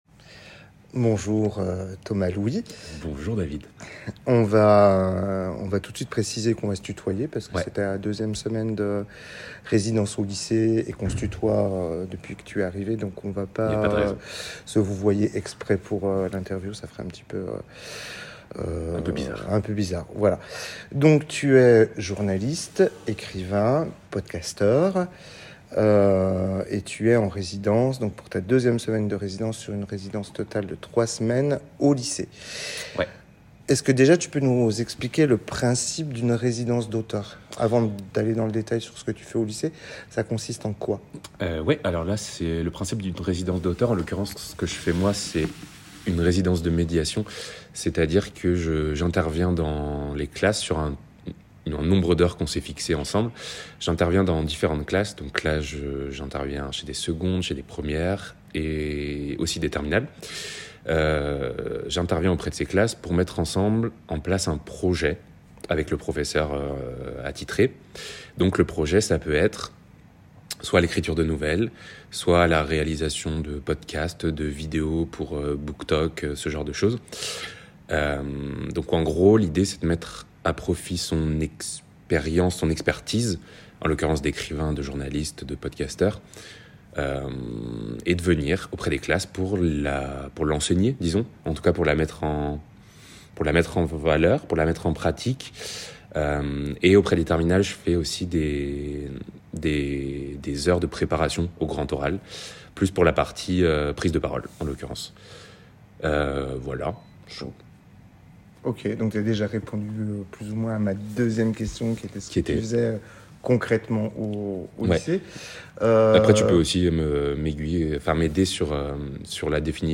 journée durant laquelle il s’est agréablement prété au jeu d’une mini interview improvisée.